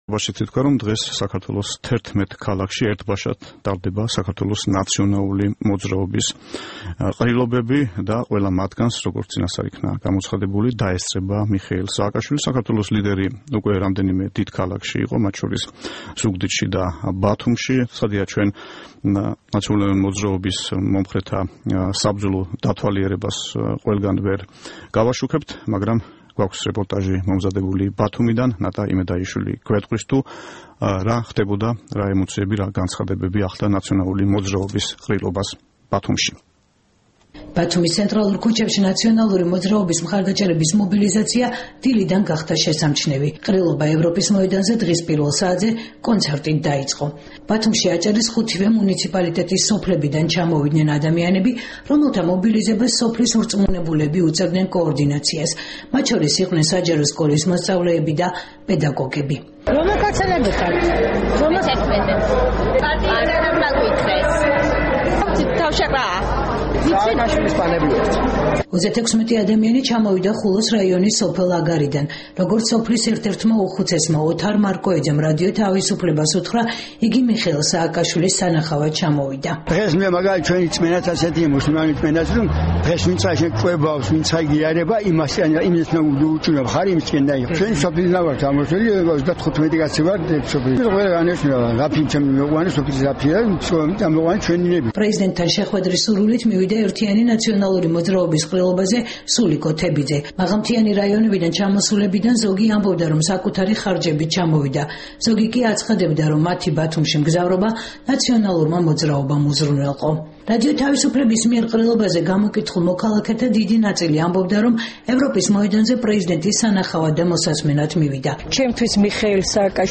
"ნაციონალური მოძრაობის“ წინასაარჩევნო შეკრება ბათუმში